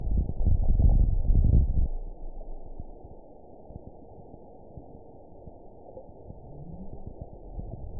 event 920263 date 03/10/24 time 19:41:25 GMT (1 year, 9 months ago) score 8.15 location TSS-AB03 detected by nrw target species NRW annotations +NRW Spectrogram: Frequency (kHz) vs. Time (s) audio not available .wav